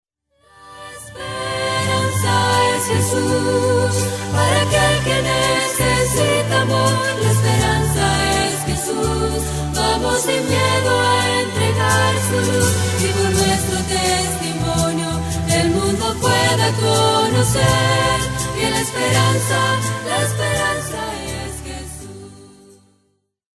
Demos